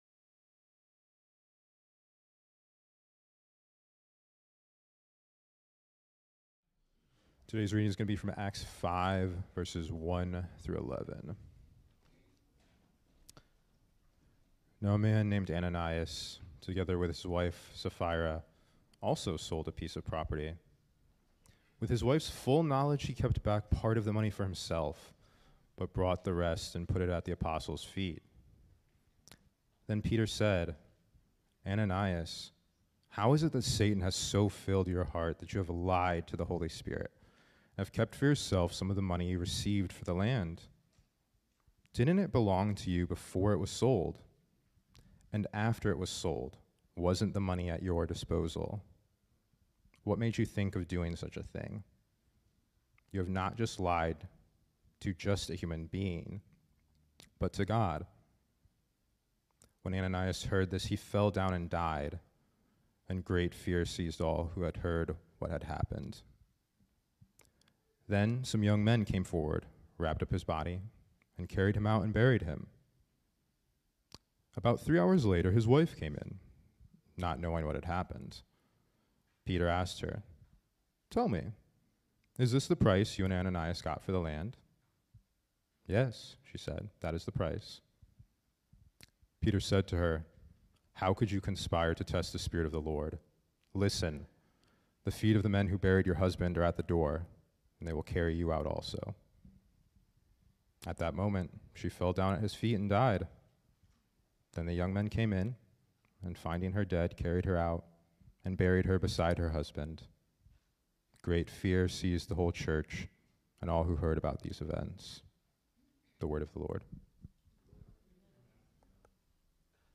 Grace Sermons | Grace Evangelical Free Church